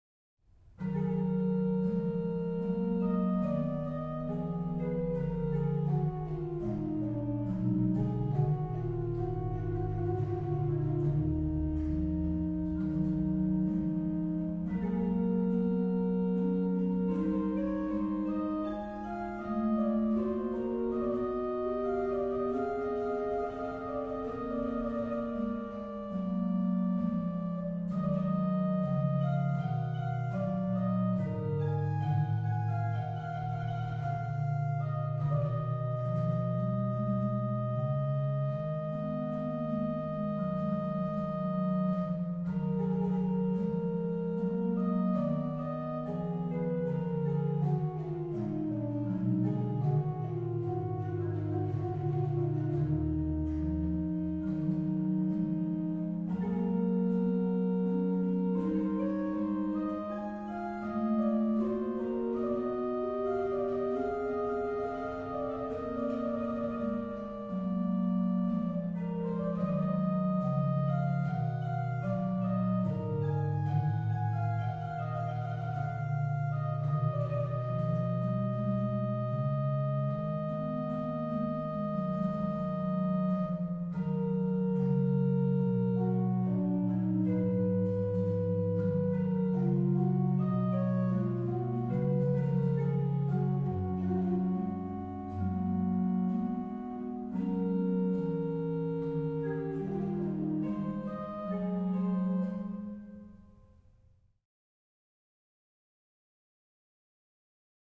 Subtitle   Fragment
Registration   rh: HW: Spz4 (8ve lower)
Trem